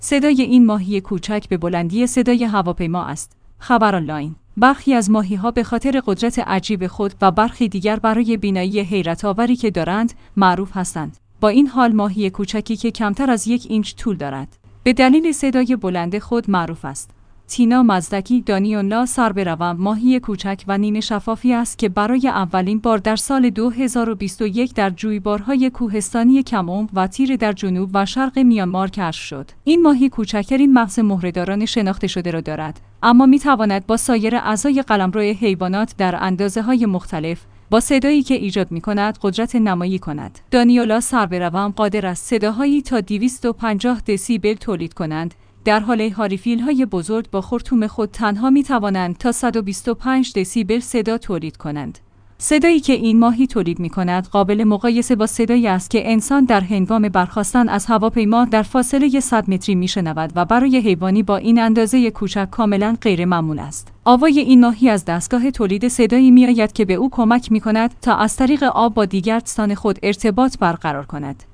صدای این ماهی کوچک به بلندی صدای هواپیما است!